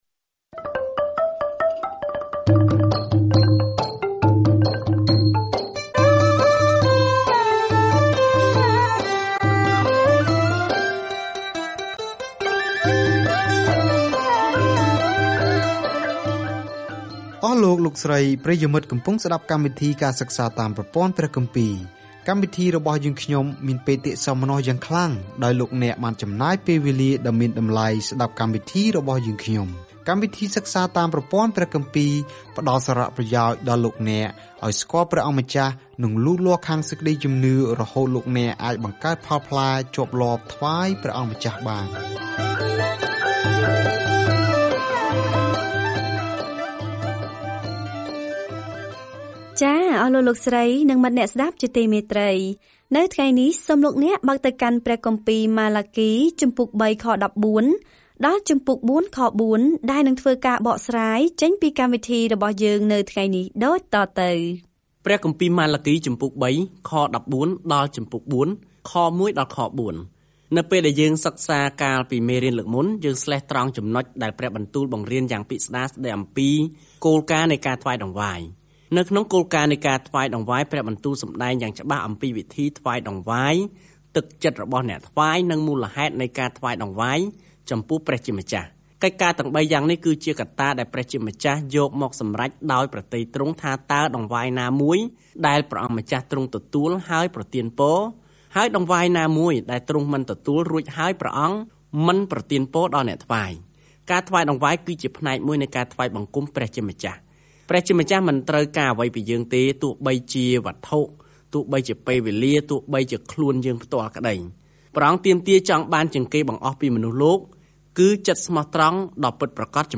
ម៉ាឡាគីរំឭកជនជាតិអ៊ីស្រាអែលដែលផ្តាច់ទំនាក់ទំនងថាគាត់មានសារពីព្រះមុនពេលពួកគេស៊ូទ្រាំនឹងភាពស្ងៀមស្ងាត់ដ៏យូរ - ដែលនឹងបញ្ចប់នៅពេលដែលព្រះយេស៊ូវគ្រីស្ទចូលដល់ឆាក។ ការធ្វើដំណើរជារៀងរាល់ថ្ងៃតាមរយៈម៉ាឡាគី នៅពេលអ្នកស្តាប់ការសិក្សាជាសំឡេង ហើយអានខគម្ពីរដែលជ្រើសរើសពីព្រះបន្ទូលរបស់ព្រះ។